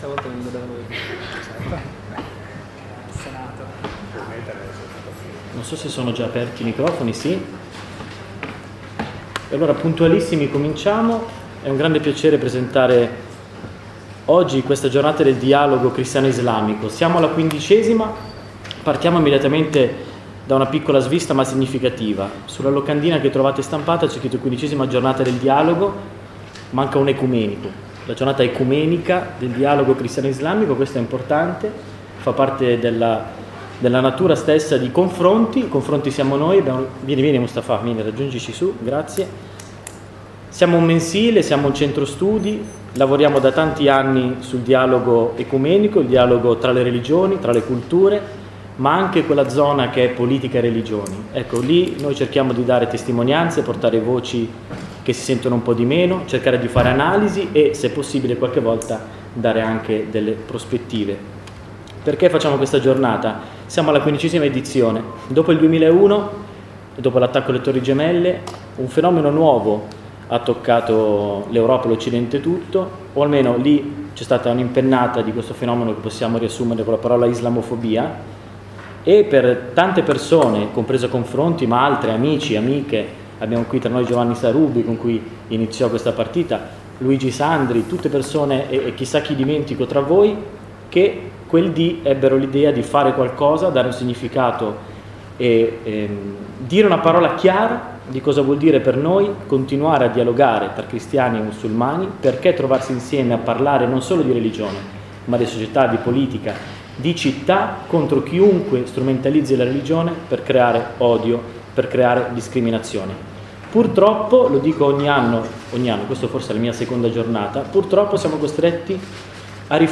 L’incontro si è aperto con i saluti istituzionali di: sen. Gianpiero Dalla Zuanna , on. Khalid Chaouki.